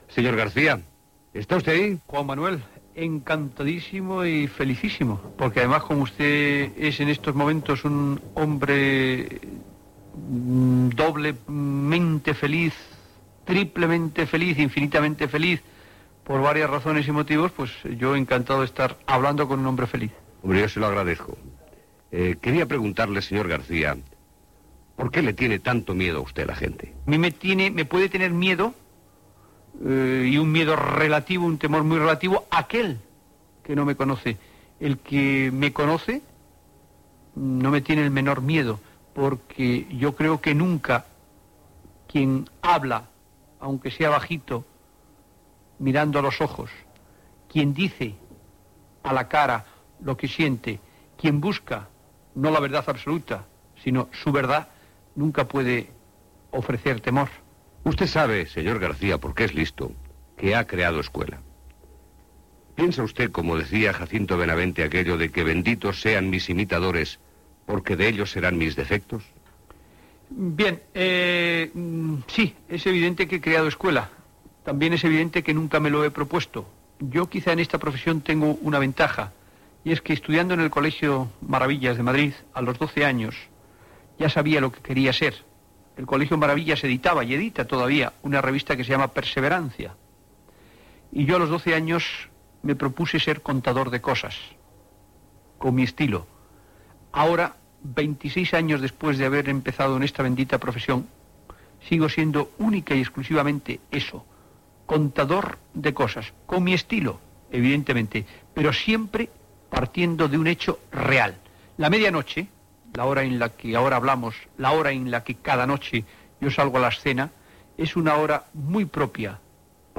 Entrevista al periodista José María García